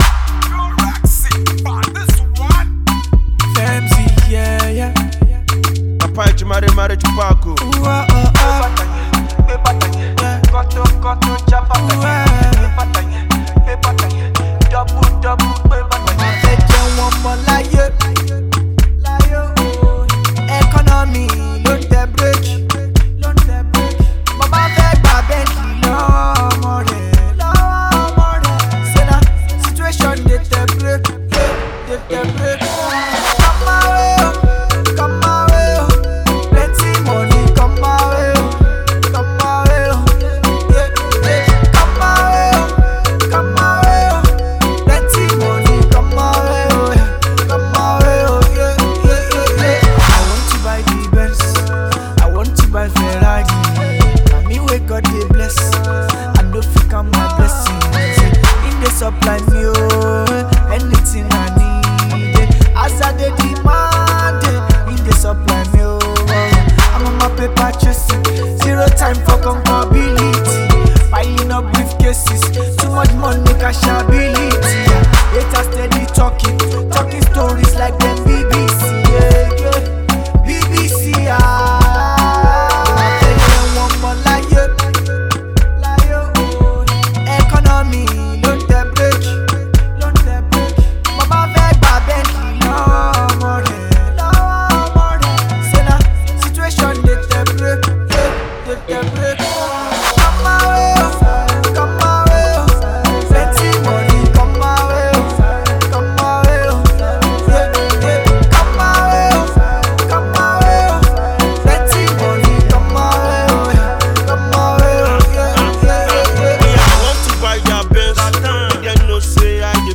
Afro-pop
with a groovy flow